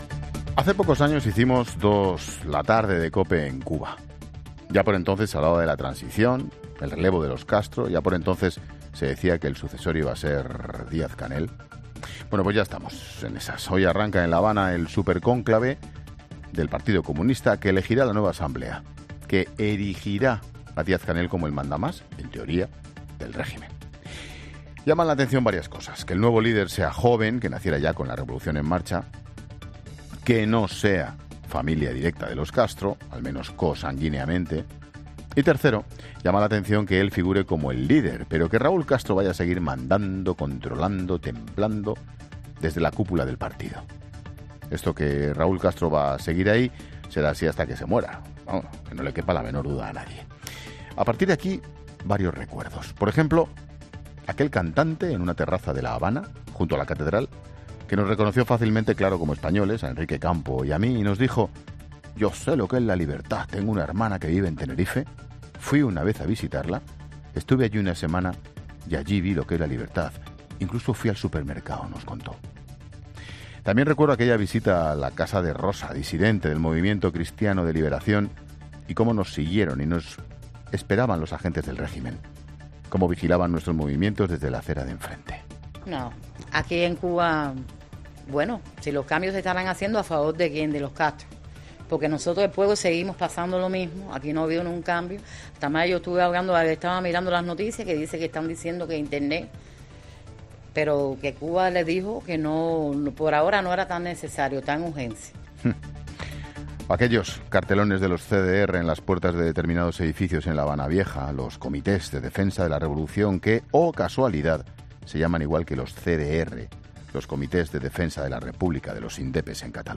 Monólogo de Expósito
El comentario de Ángel Expósito